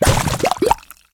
slime.ogg